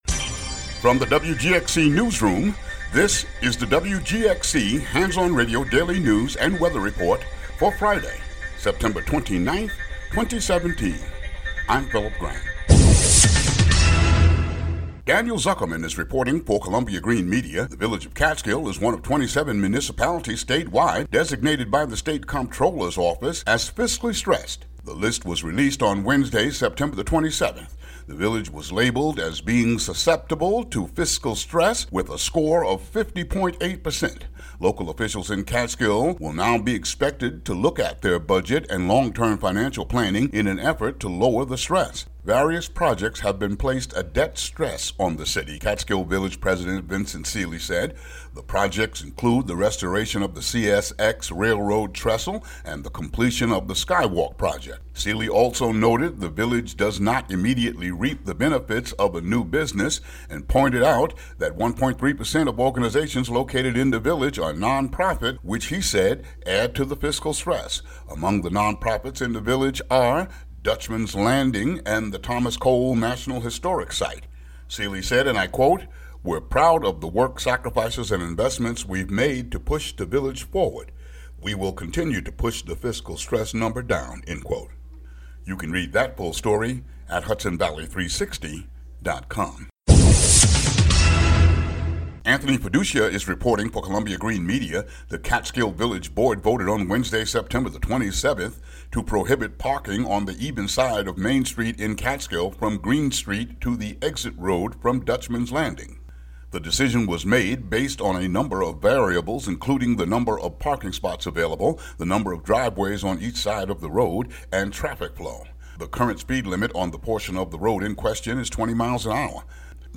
WGXC Local News Audio Link
WGXC daily headlines for Sep. 29, 2017.